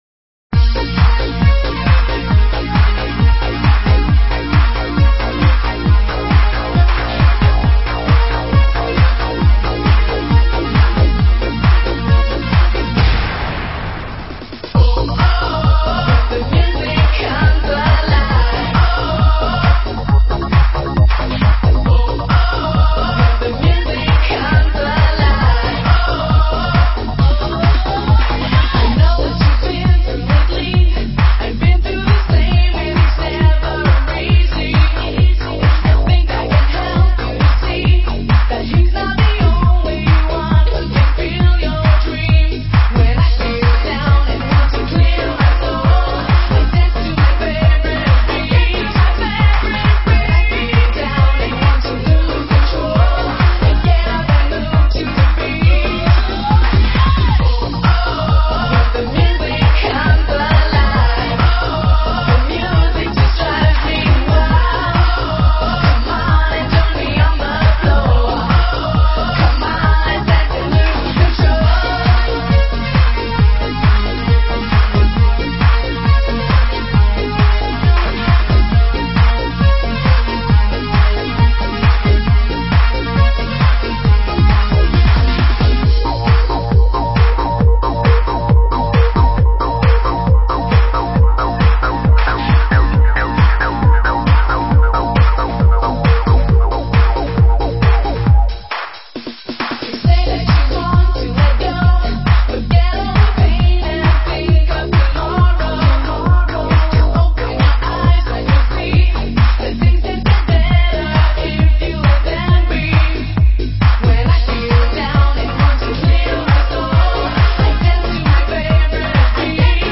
Назад в Exclusive EuroDance 90-х